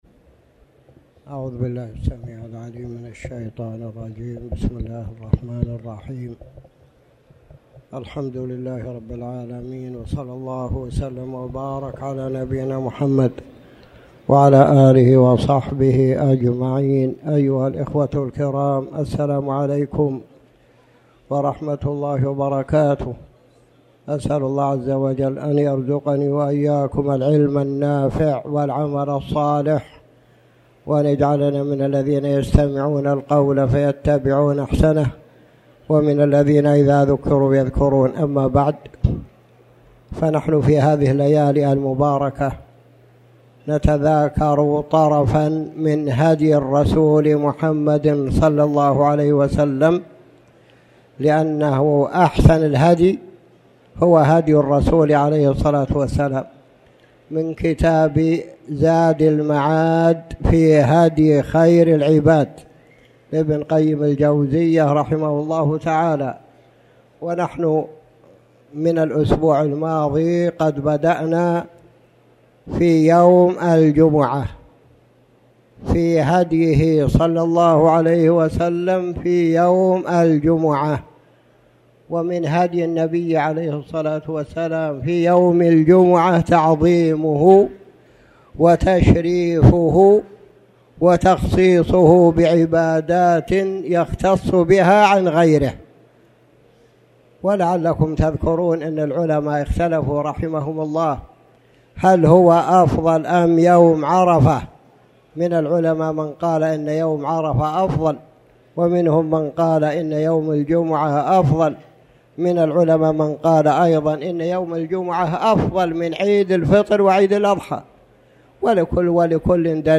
تاريخ النشر ٢١ ذو الحجة ١٤٣٩ هـ المكان: المسجد الحرام الشيخ